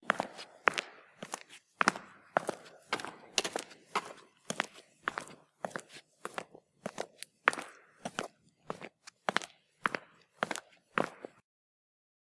footsteps.ogg